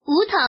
Index of /mahjong_gansu_test/update/1686/res/sfx/woman/